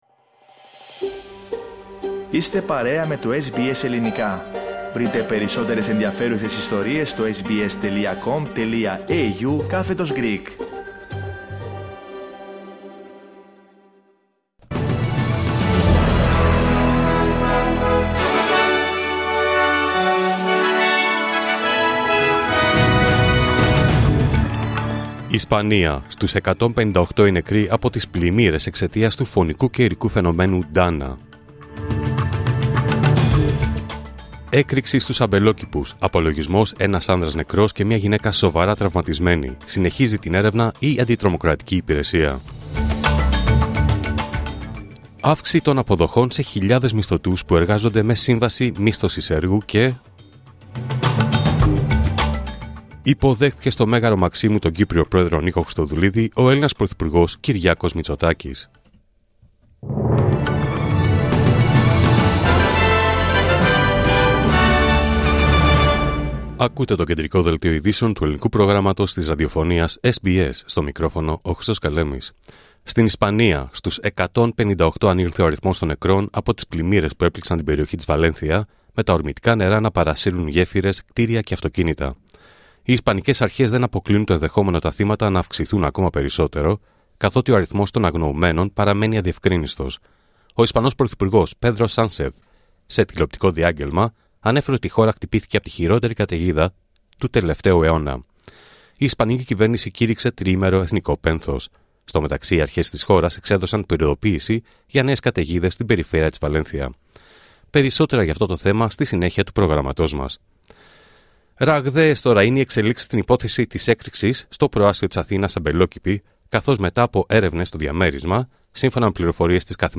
Δελτίο Ειδήσεων Παρασκευή 1 Νοέμβριου 2024